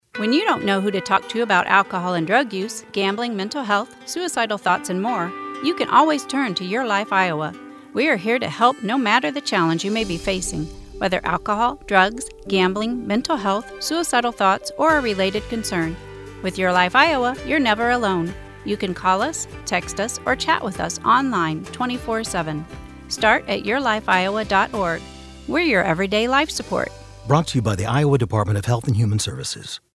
:30 Radio Spot | YLI Awareness (Female-2)